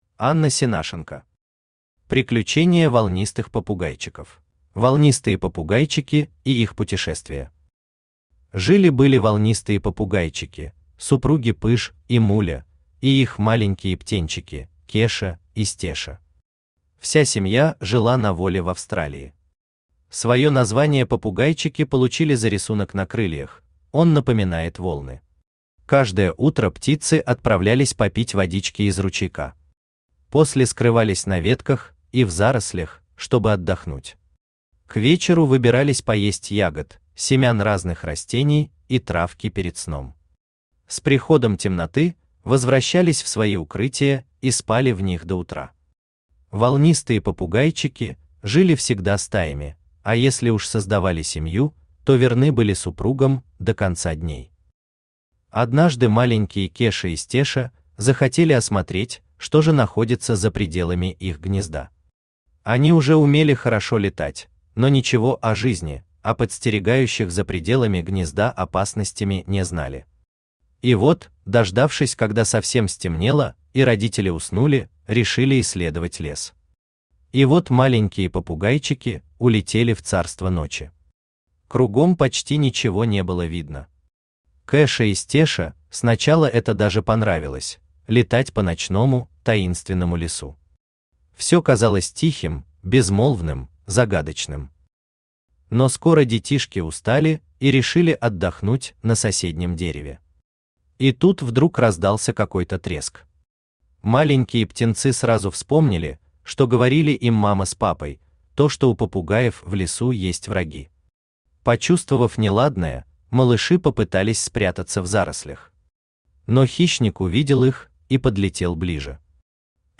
Аудиокнига Приключения волнистых попугайчиков | Библиотека аудиокниг
Aудиокнига Приключения волнистых попугайчиков Автор Анна Николаевна Сенашенко Читает аудиокнигу Авточтец ЛитРес.